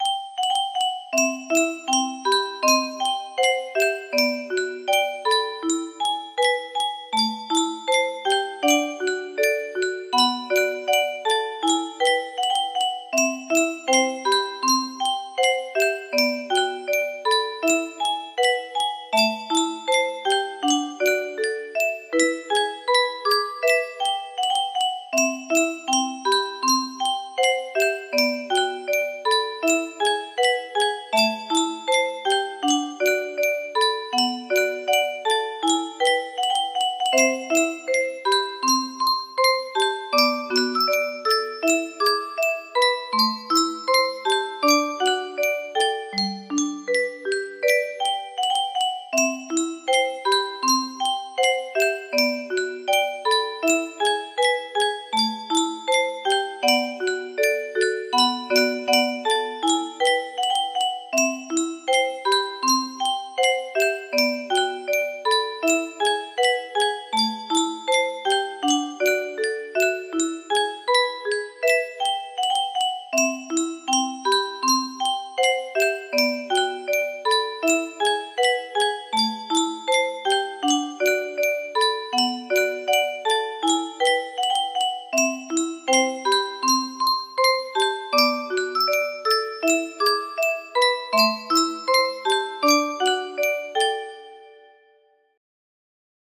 Some notes had to be subsituted, and I apologize for that.